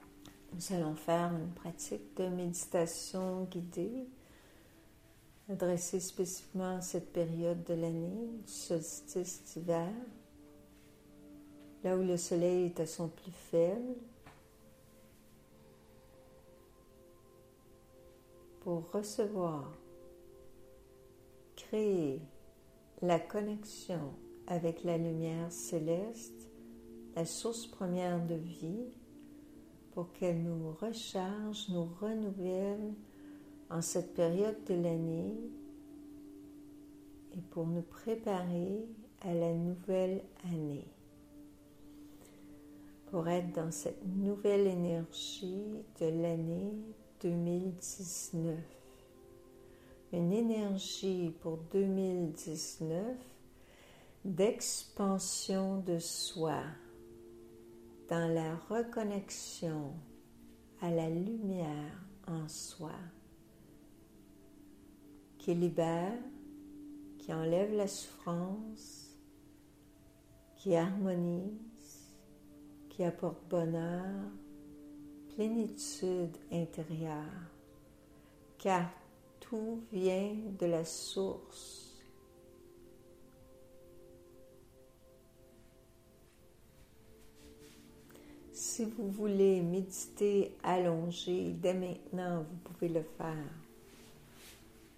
Méditation du solstice d’hiver